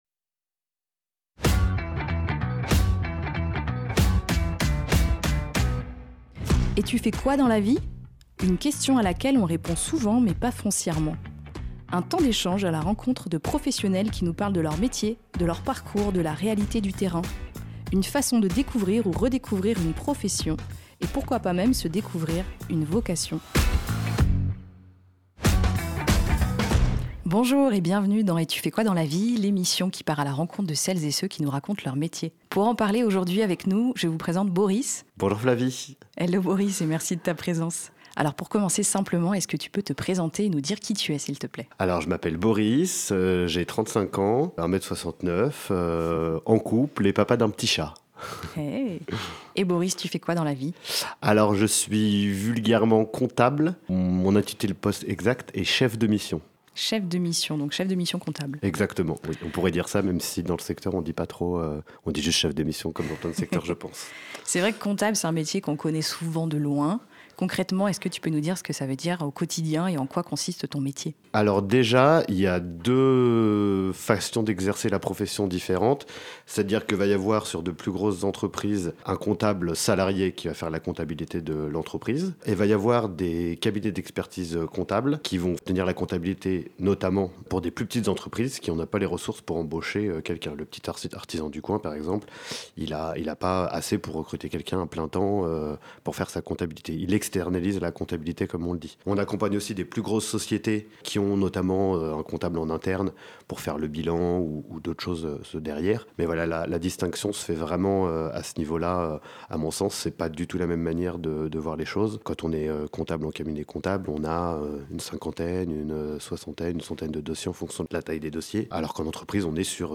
Découvrez des conversations sincères avec des professionnels authentiques qui sauront, qui sait, éveiller chez vous, une ou des vocations.